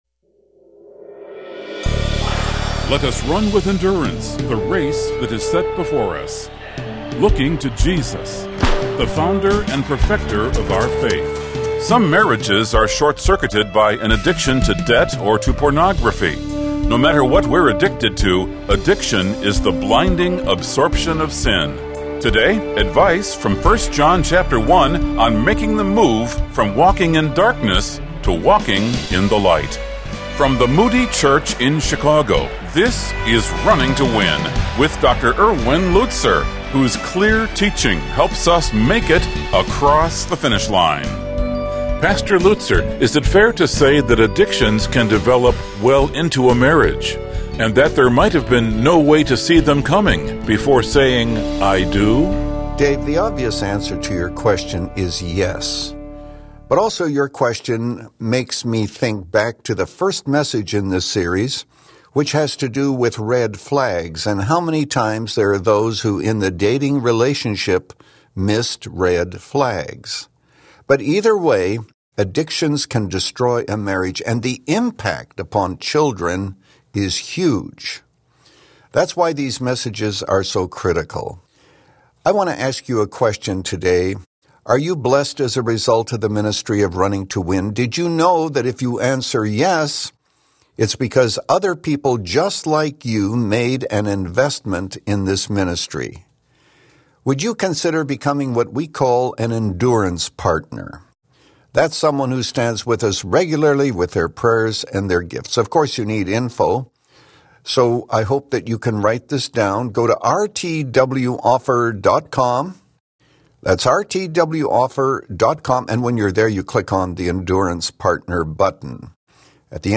In this message from 1 John 1, Pastor Lutzer diagnoses four symptoms of walking in darkness: shame, fear, self-deception, and self-rationalization.